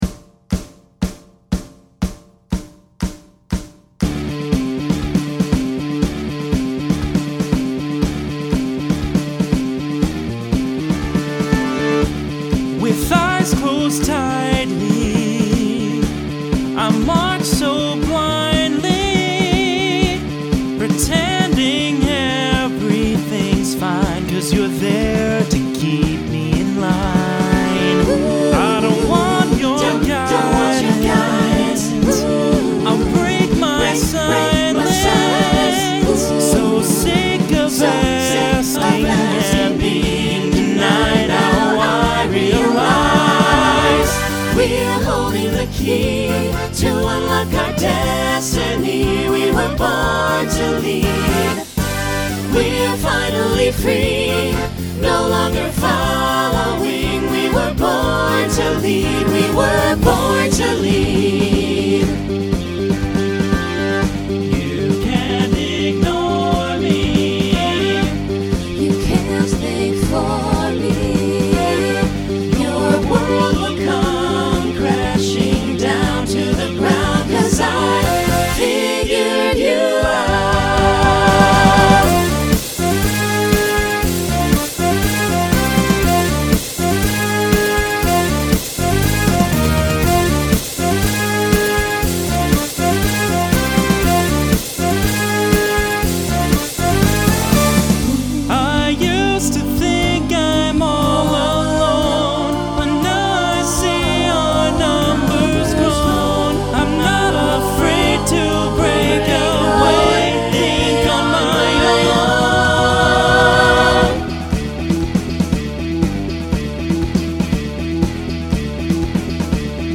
Genre Rock Instrumental combo
Voicing SATB